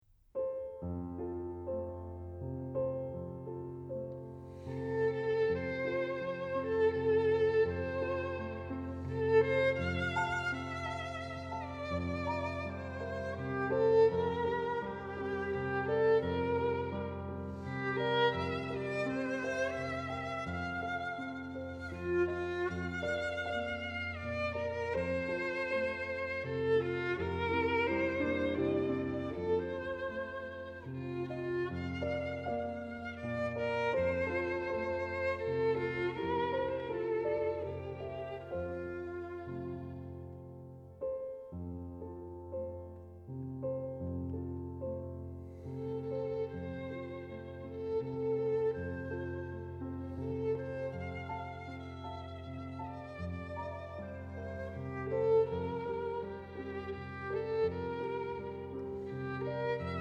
Violin
Piano)audio_joy.JPG